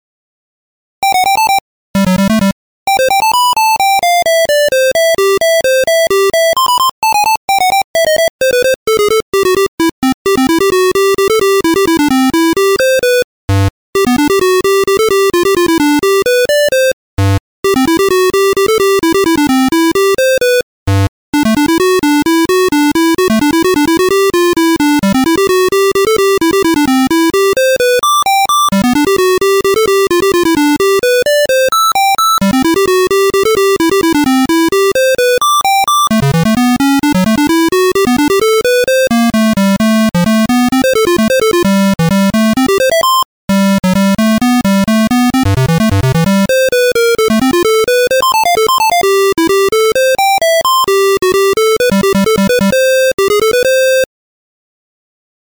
DOS QBasic Emulation